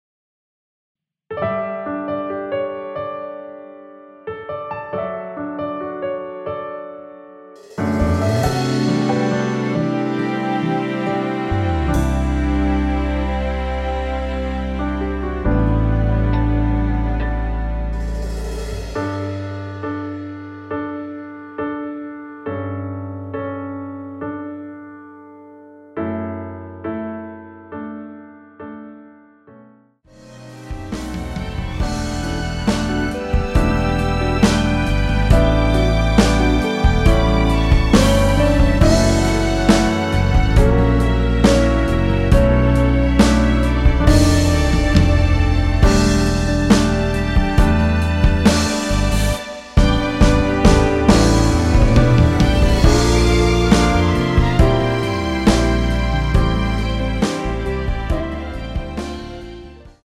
원키에서(-1)내린 1절후 후렴으로 진행 되는 MR입니다.
D
앞부분30초, 뒷부분30초씩 편집해서 올려 드리고 있습니다.
중간에 음이 끈어지고 다시 나오는 이유는